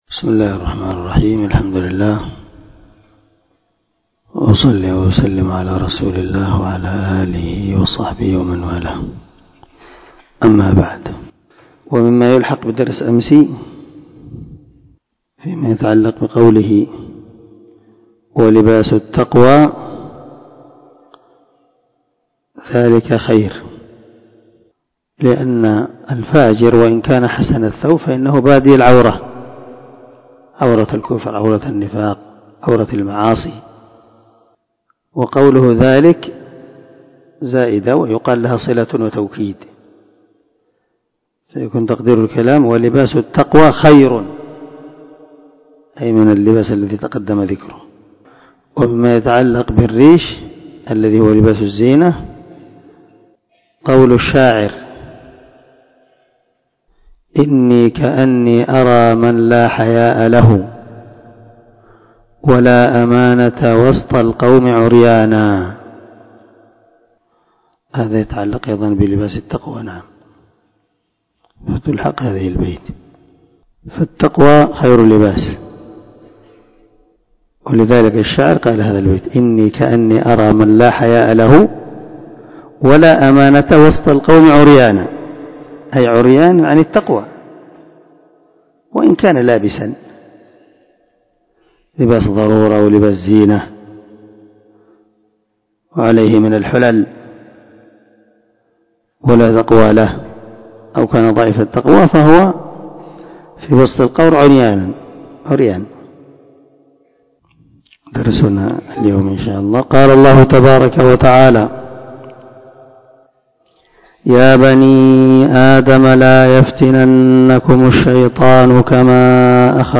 454الدرس 6 تفسير آية ( 27 ) من سورة الأعراف من تفسير القران الكريم مع قراءة لتفسير السعدي